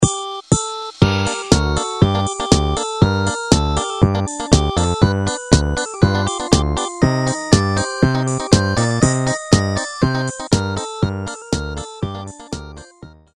Siemens полифония. Шансон